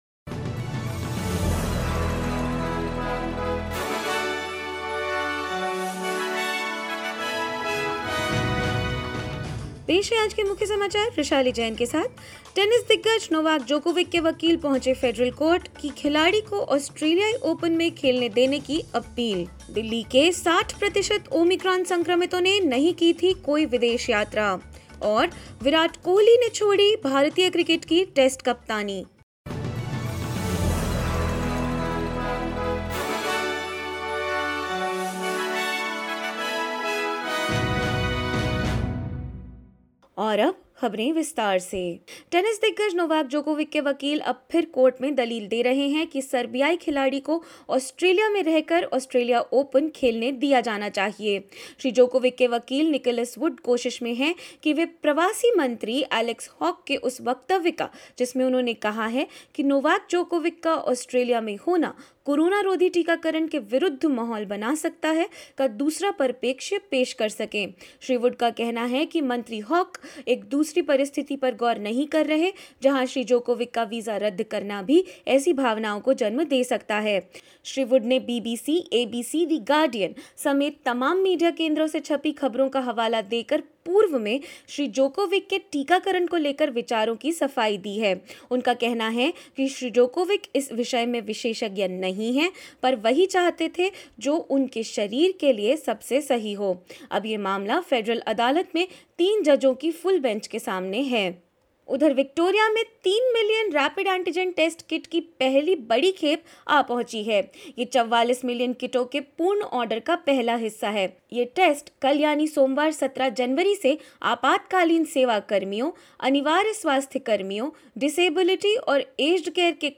In this latest SBS Hindi bulletin: Tsunami warnings have been issued for Australia's east coast after an underwater volcano erupted off the coast of Tonga; Novak Djokovic appeals in federal court against rejection of his visa; Virat Kohli resigns from test captainship of Indian cricket squad and more news.
hindi_news_1601.mp3